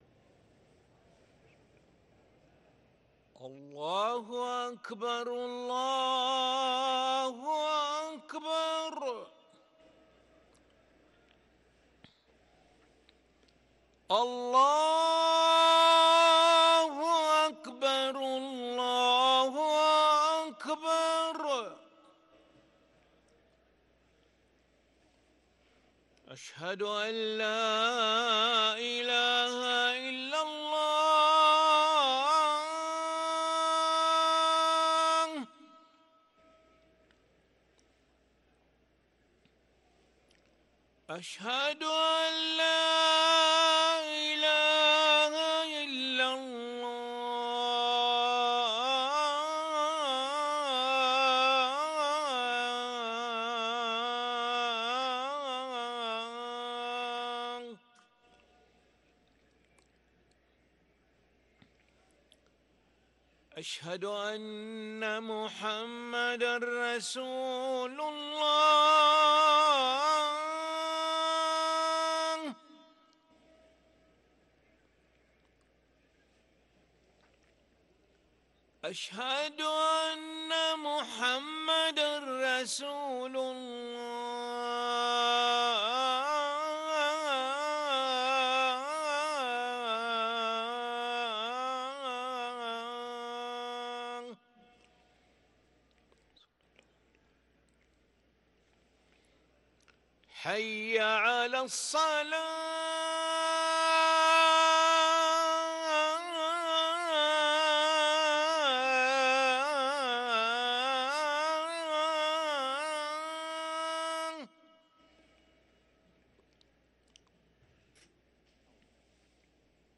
أذان العشاء للمؤذن علي ملا الأحد 1 جمادى الآخرة 1444هـ > ١٤٤٤ 🕋 > ركن الأذان 🕋 > المزيد - تلاوات الحرمين